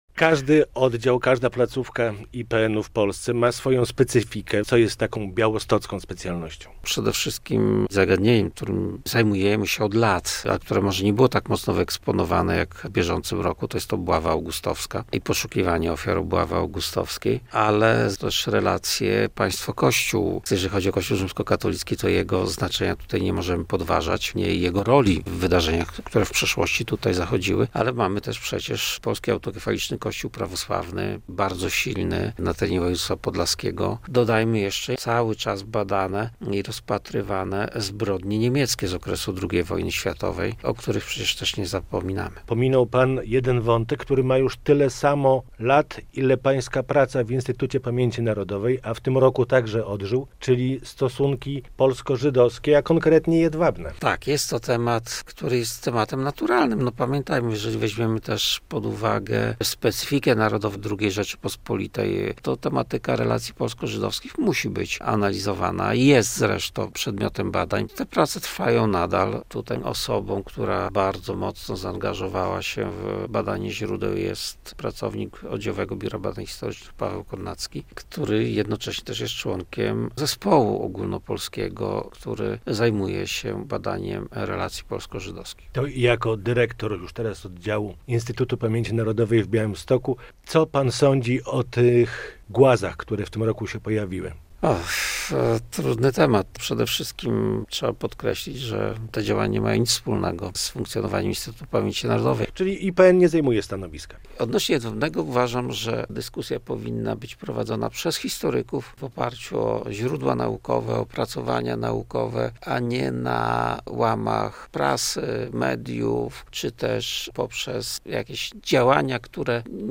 Radio Białystok | Gość | Krzysztof Sychowicz - dyrektor oddziału Instytutu Pamięci Narodowej w Białymstoku